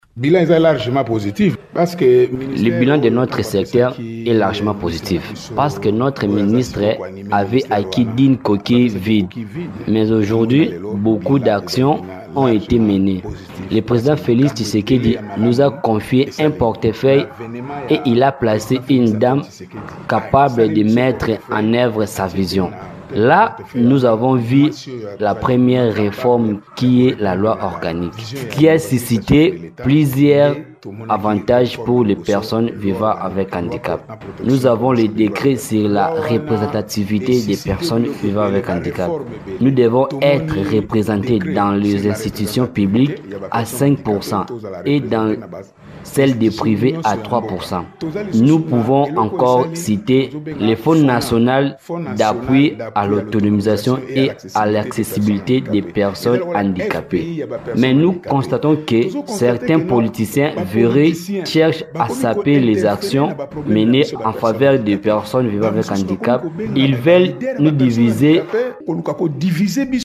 Dans un entretien accordé mardi 20 janvier à Radio Okapi